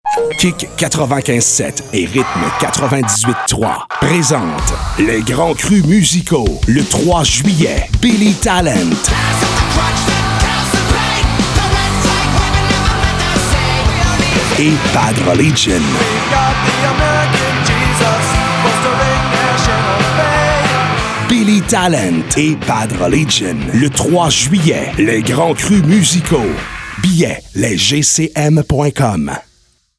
PROMO RADIO BILLY TALENT & BAD RELIGION
promo-radio-les-grands-crus-3-juillet-2025.mp3